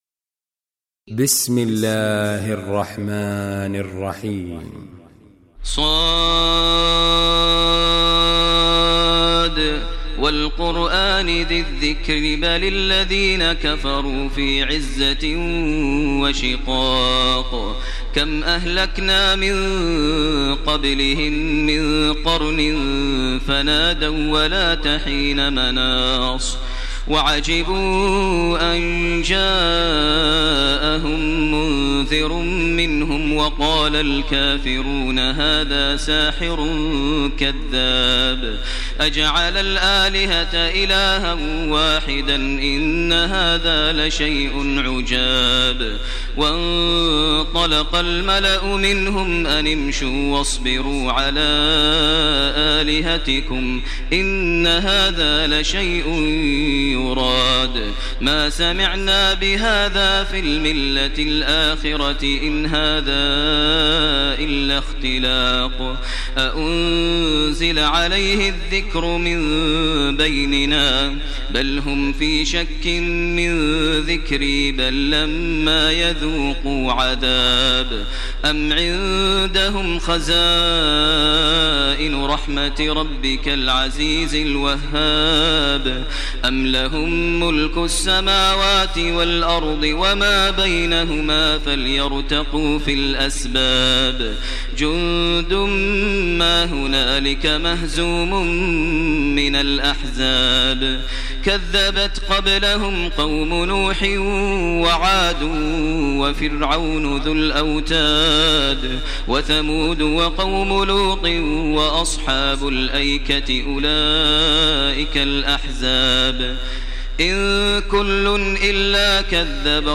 Surah Sad Recitation by Sheikh Maher al Mueaqly